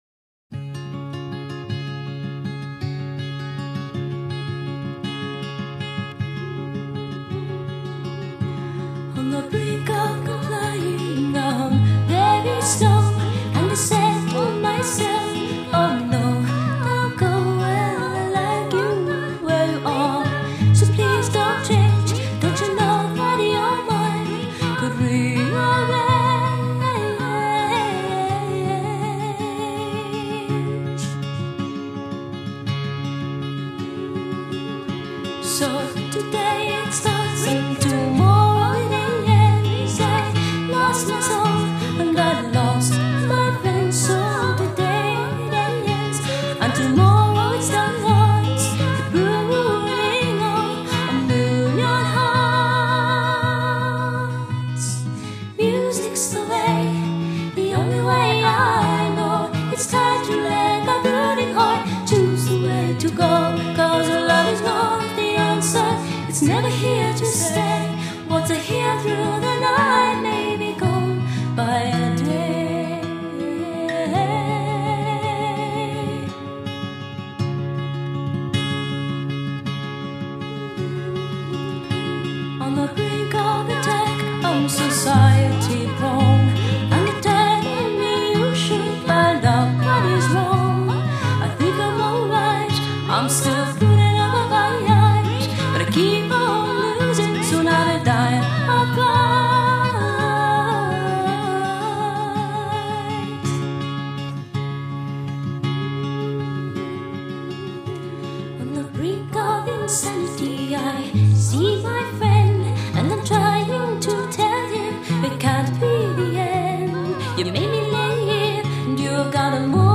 guitar/vocal or guitar/vocal/electronics